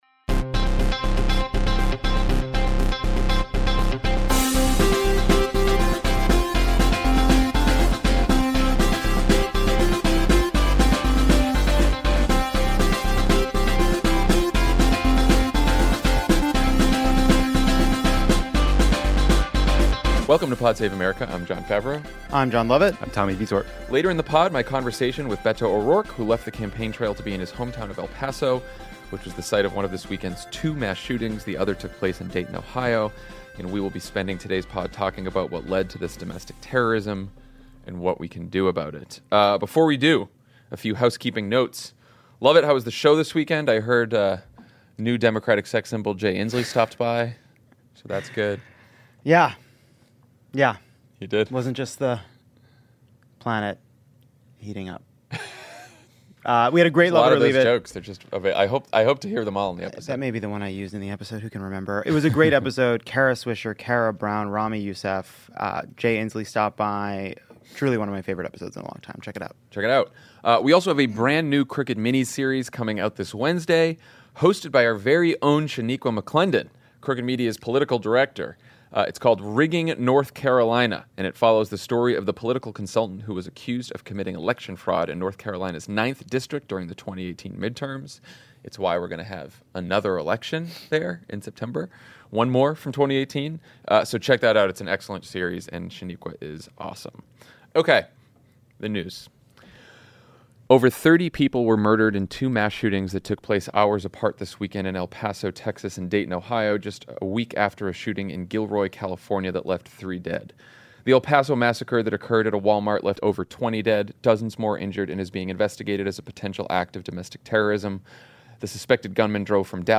A white nationalist terror attack in El Paso, Texas and a mass shooting in Dayton, Ohio focus the country’s attention on the President’s racism and America’s gun violence crisis. Then Democratic presidential candidate Beto O’Rourke talks to Jon F. about the terrorist attack on his hometown and what we can do about the threat of white nationalism.